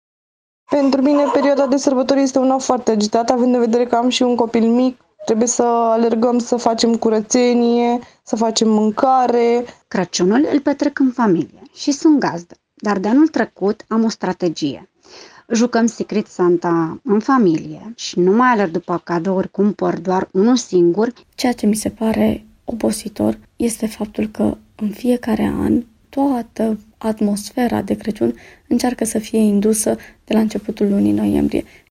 Brașovenii confirmă că este o perioadă agitată și obositoare: